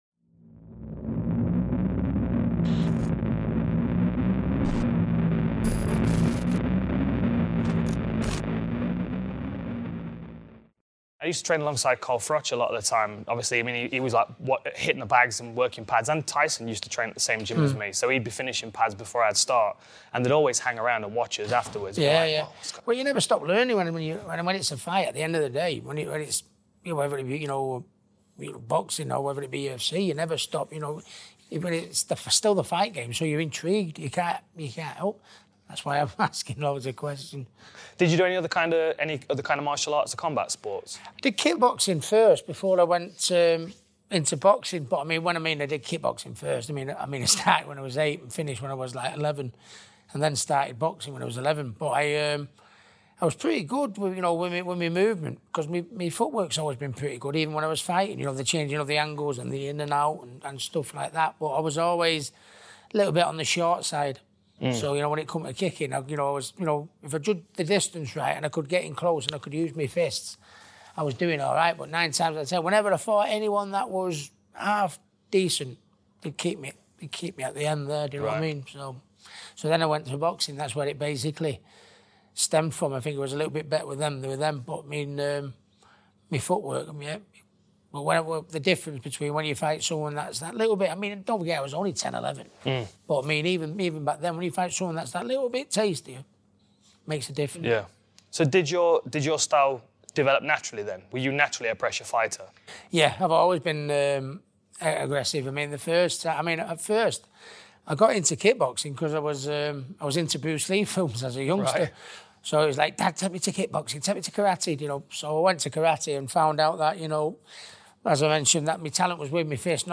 Dan Hardy interviews Ricky Hatton ahead of Marco Antonio Barrera fight